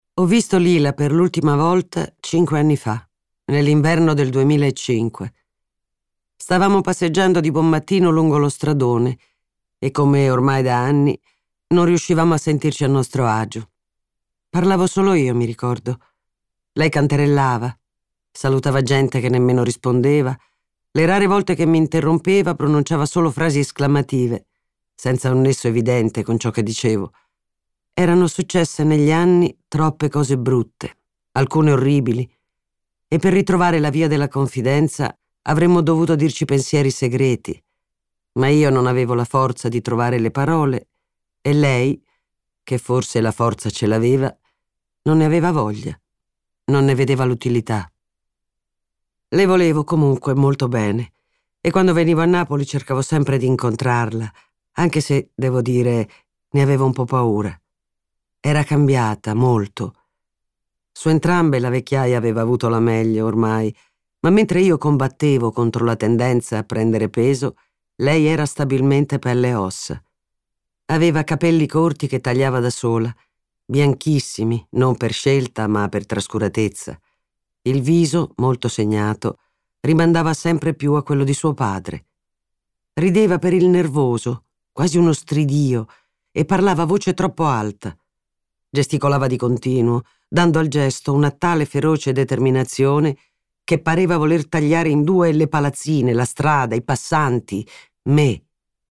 Audiolibro Emons audiolibri 2016